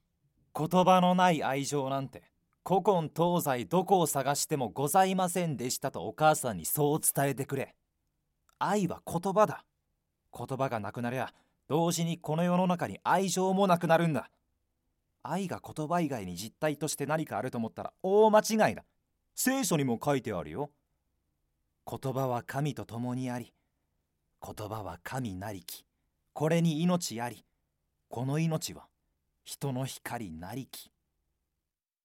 セリフ@